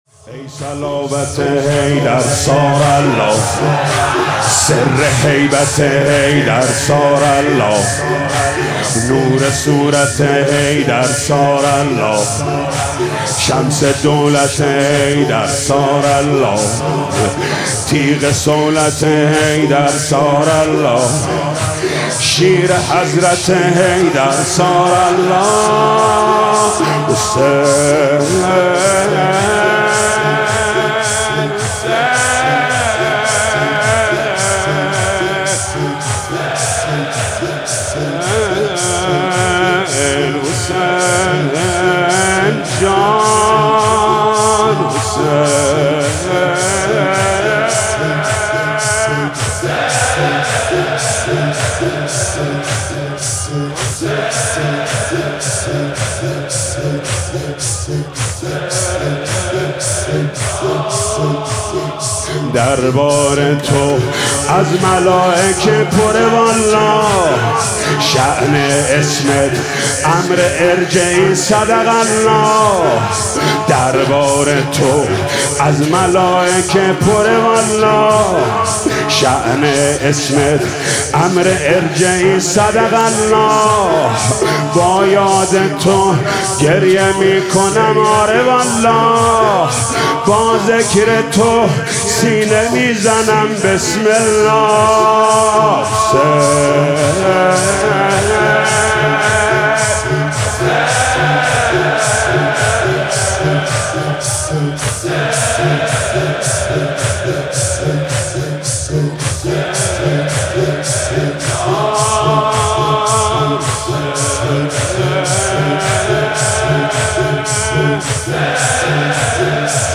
مراسم عزاداری شب اوّل محرم ۱۴۰۳ با صدای محمود کریمی
بخش دوم - روضه (آمیختند با خون، خون دلی که خوردیم)